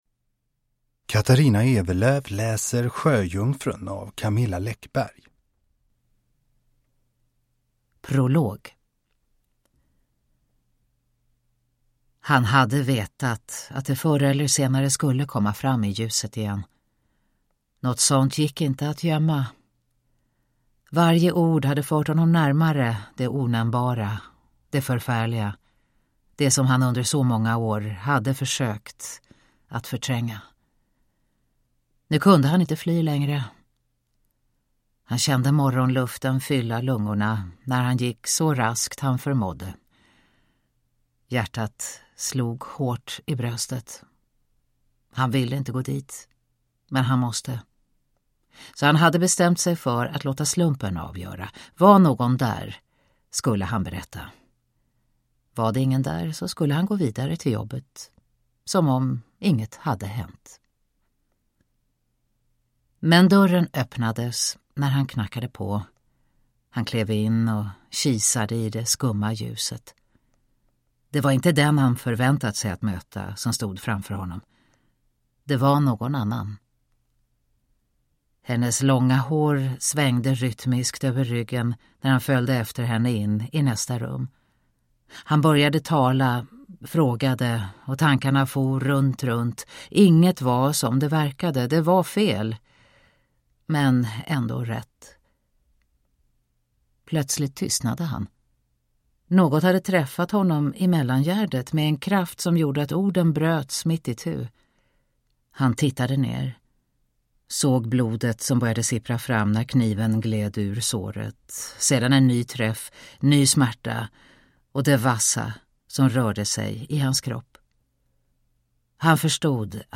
Sjöjungfrun / Ljudbok
Uppläsare: Katarina Ewerlöf